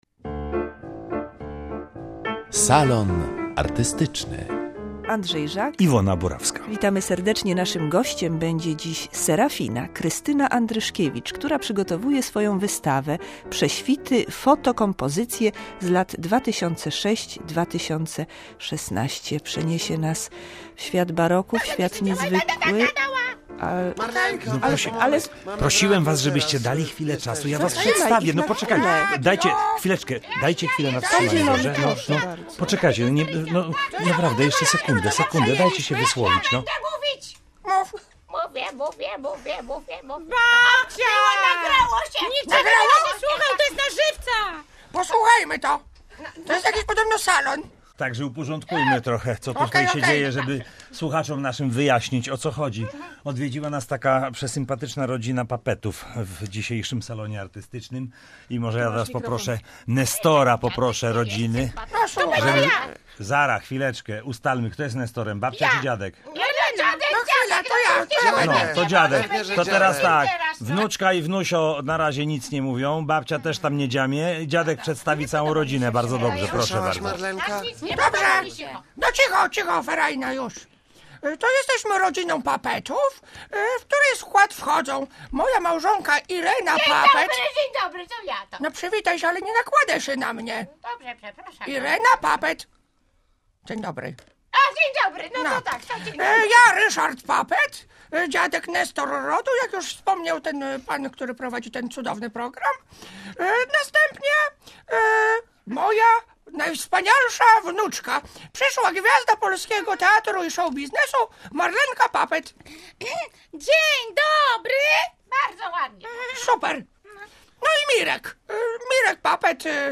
Rozmawiamy z wybitną artystką i sprawdzamy, kim są puppety